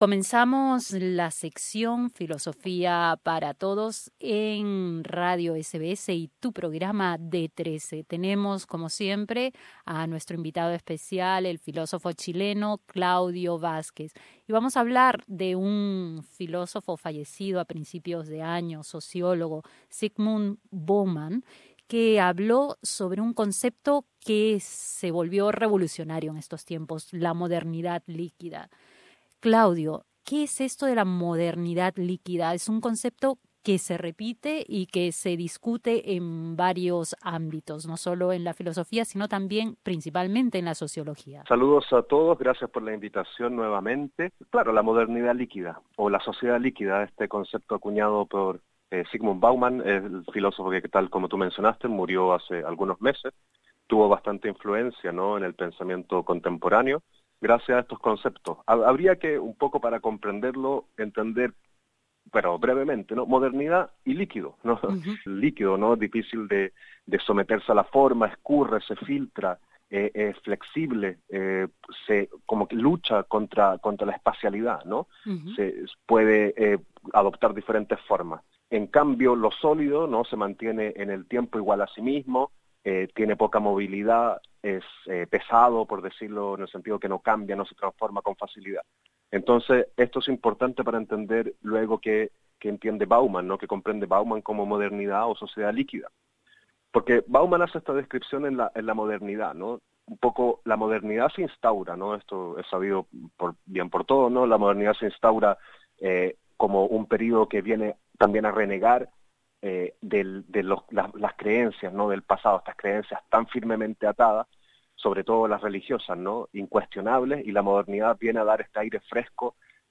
En nuestra sección Filosofía para todos conversamos con el filósofo chileno